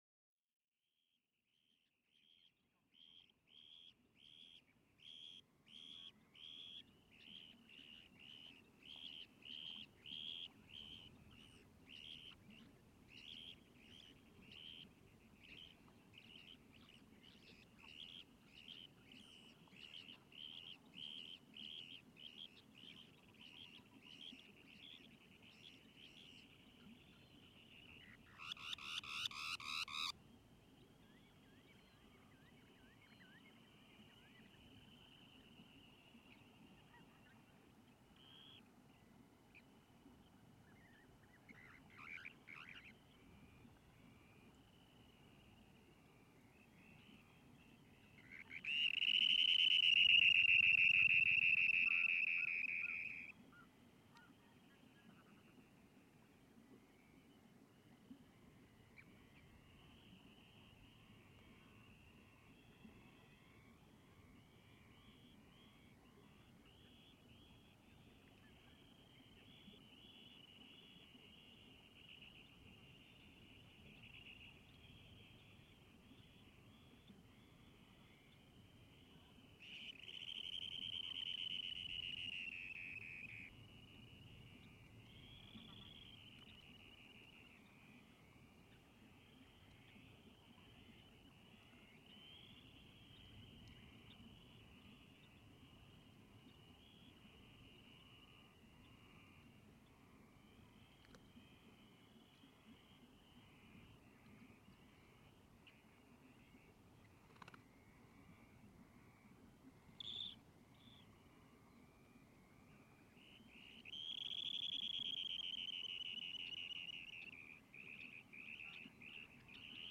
Howling fox
I just like to say, it was recorded in the highland in one of my „hidden places“ that still exists and has not been destroyed with hydro power plant, tourists, offroad driving motorist, or human waste. This recording is very quiet. It contains a dunlin, howling arctic fox, europian golden plover, red necked phalarope and pink footed goose. The background noise is a waterflow from nearby water source. This was recorded 17th of July 2015 around two o´clock in the morning. Quality open headphones are recommended while listening at low to mid level, or in speakers at low level .